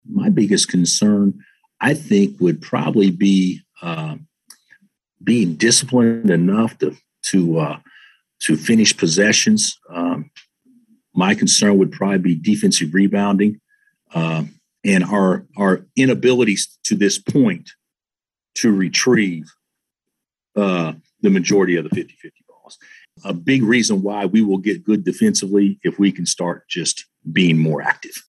Kansas Coach Bill Self says they need to improve defensively.
1-11-bill-self-defensive-concerns.mp3